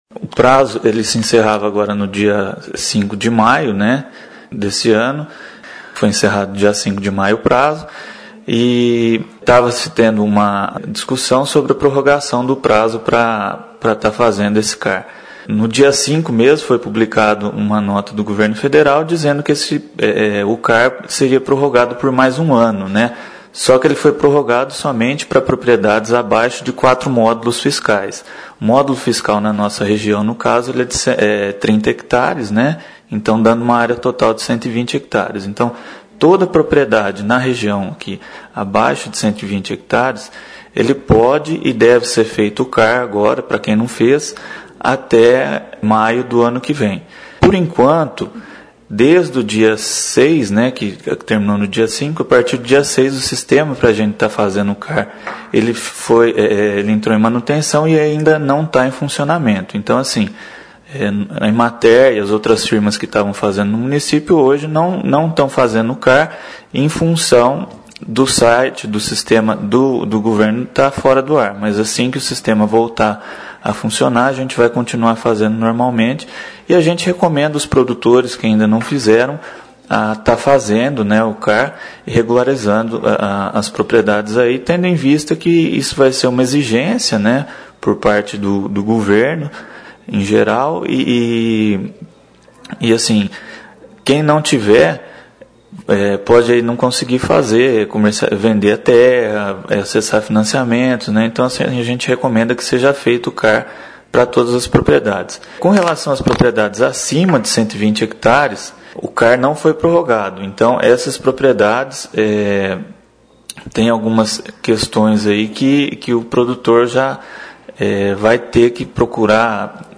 Apenas propriedades rurais com até 120 hectares é que terão o prazo prorrogado. (Clique no player e ouça a entrevista).